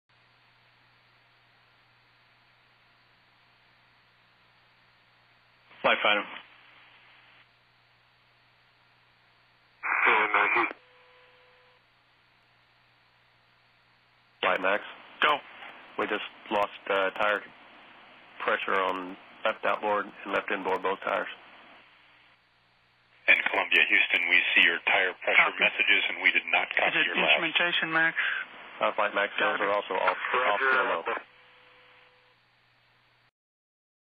I ran the audio out to loss of signal at 8:59:32, but removed some silent periods.
Also, the conversation you hear, is going on between the mission control people, not between Columbia and the ground.